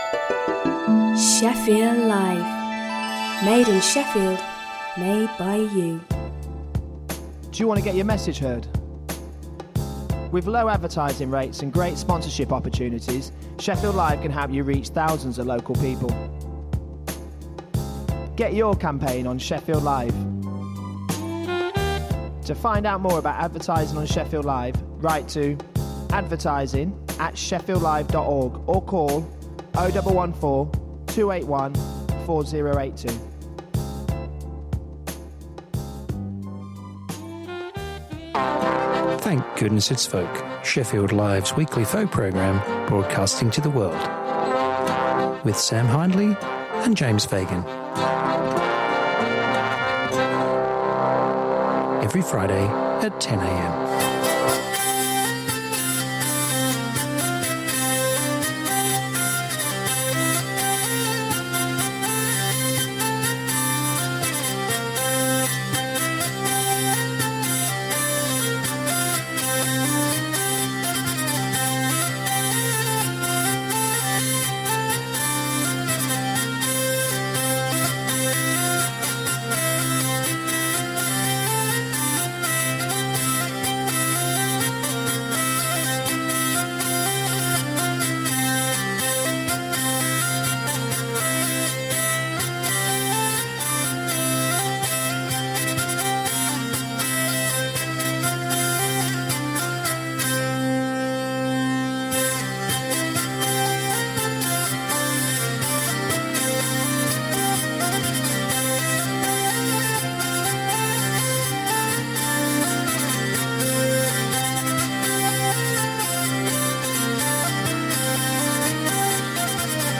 Traditional folk music from the British Isles